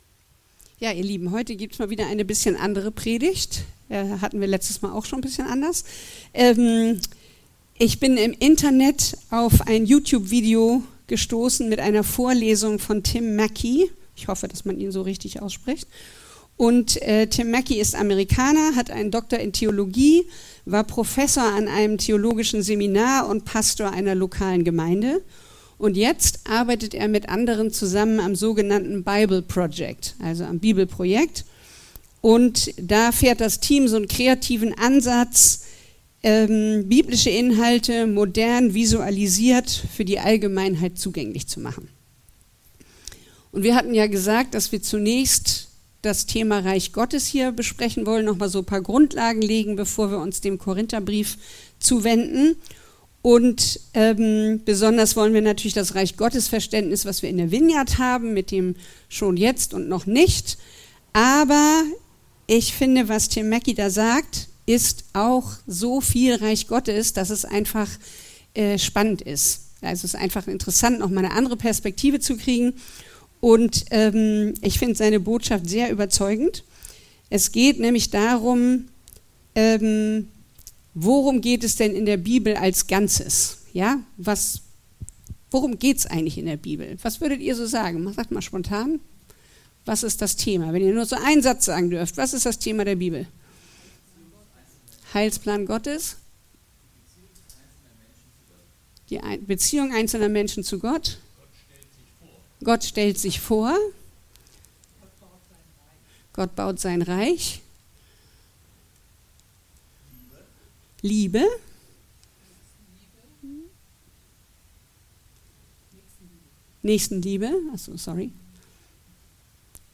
Predigt Gottesdienst: Sonntag